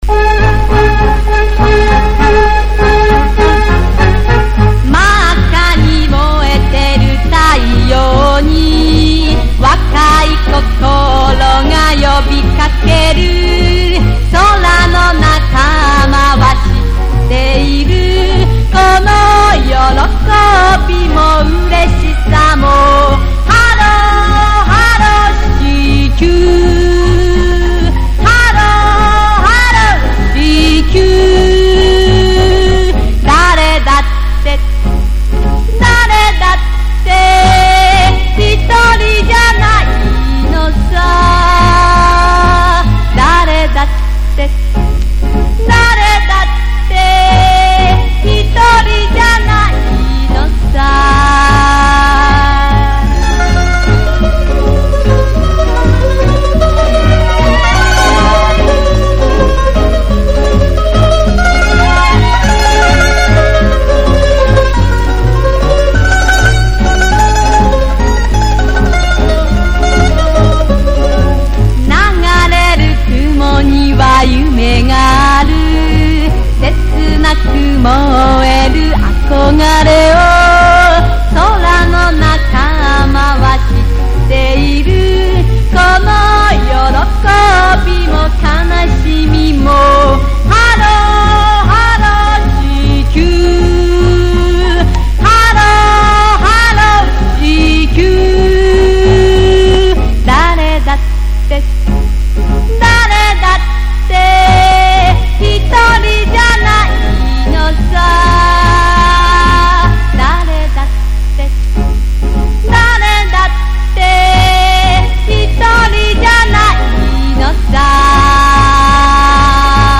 主題歌のレコードジャケットです　中身は本当はソノシート（ソノシートって？）